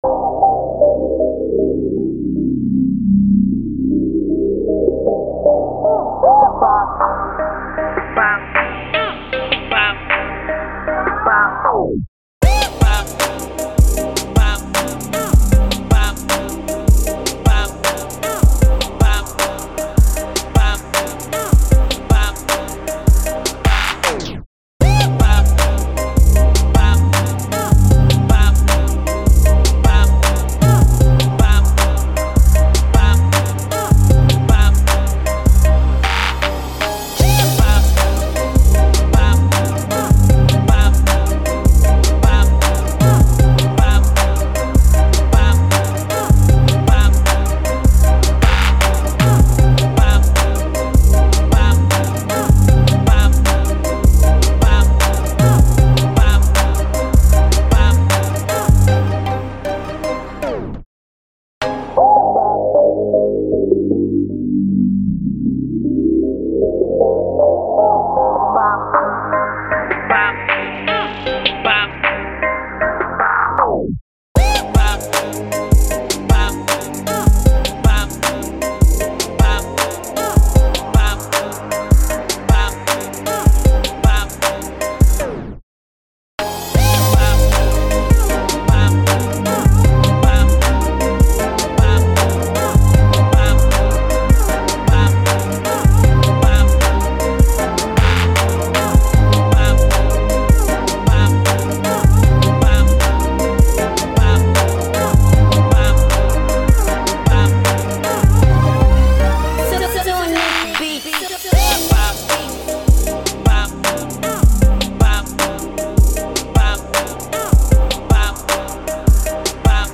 A Trap hardcore rap beat